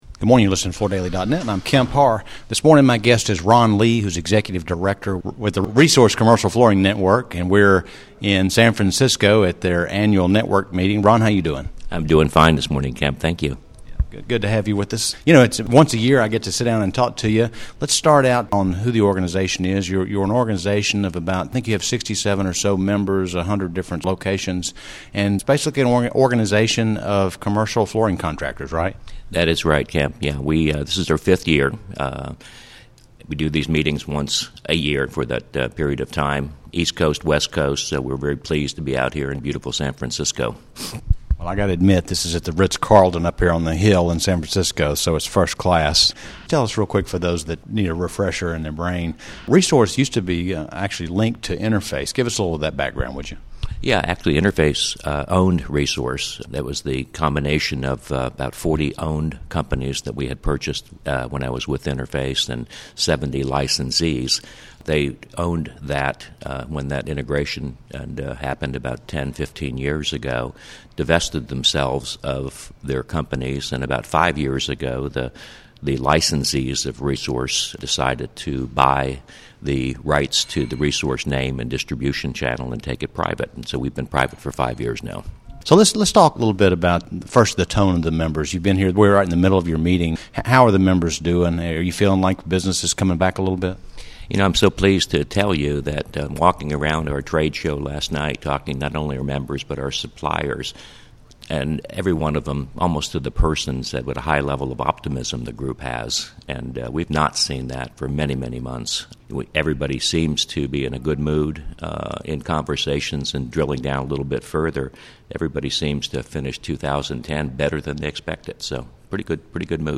Listen to the interview to hear the highlights of their meeting in San Francisco and more details around business conditions within this group of commercial flooring contractors.